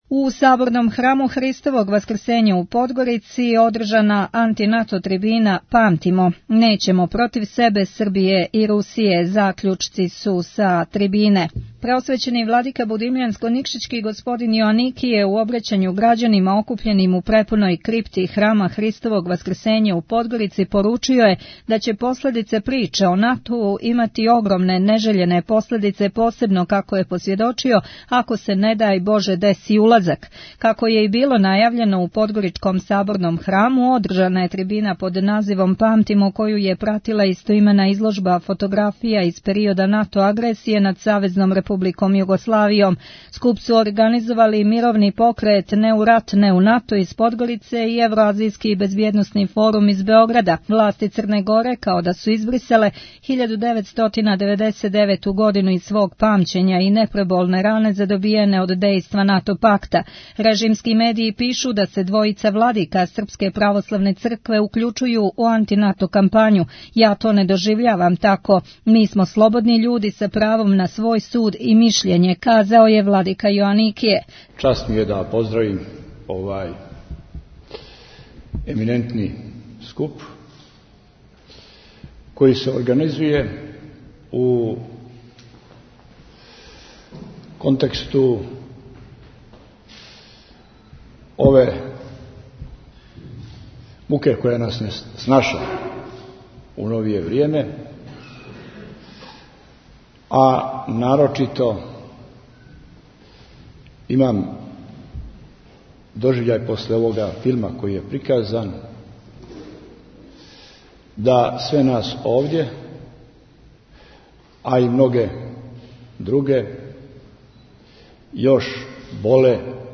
У Саборном храму у Подгорици одржана анти - НАТО трибина „Памтимо“: Нећемо против себе, Србије и Русије!